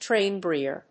音節tráin・bèarer